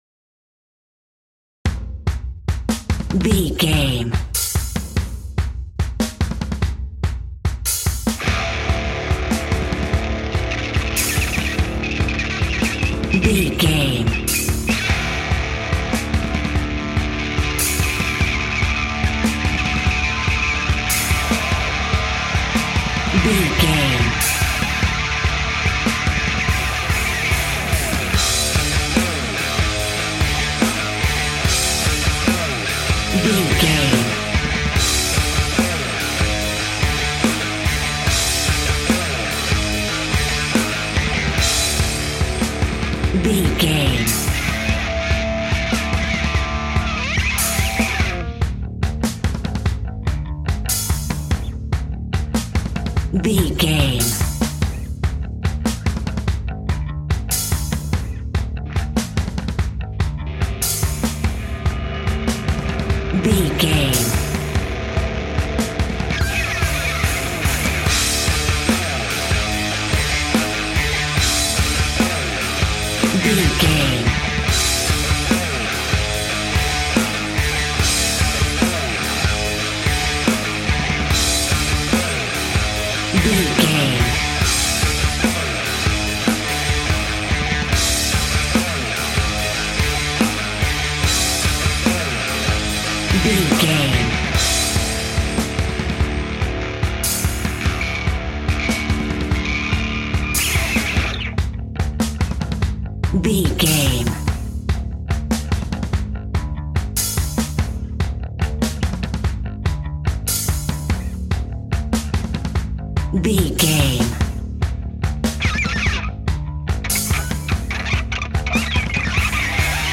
Ionian/Major
Fast
energetic
driving
heavy
aggressive
electric guitar
bass guitar
drums
hard rock
heavy metal
horror rock
instrumentals